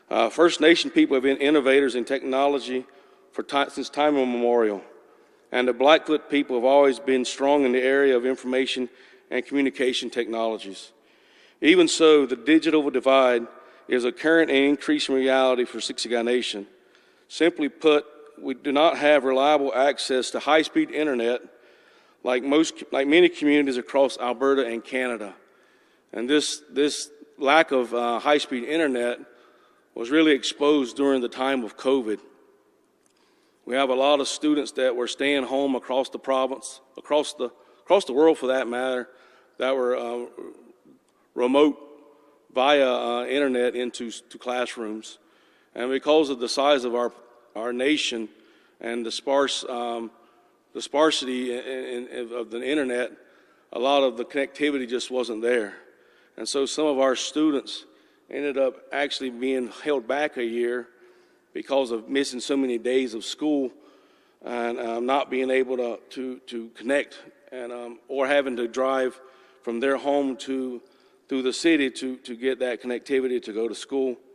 Photo of Chief Ouray Crowfoot speaking about the importance of having reliable stable internet connection at Blackfoot Crossing Historic Site
Chief Ouray Crowfoot of Siksika shared how having access to high quality internet will improve community connection in Siksika.